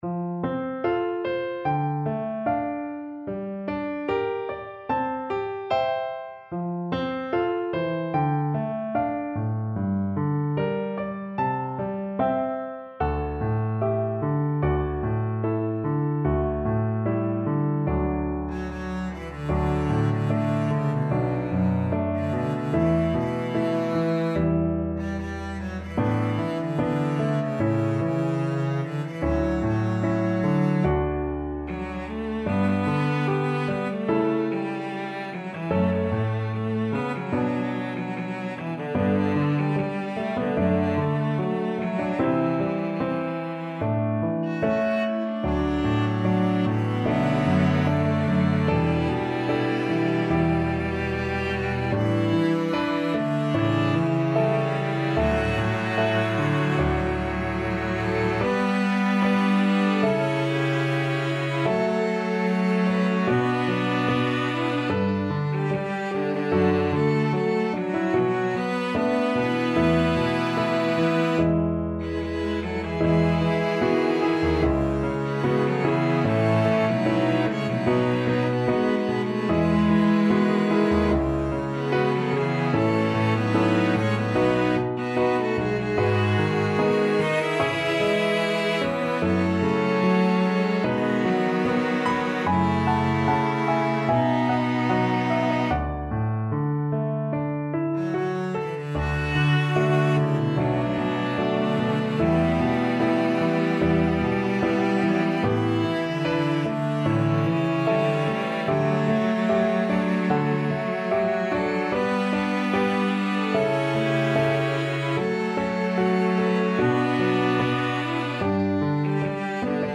SATB + piano/band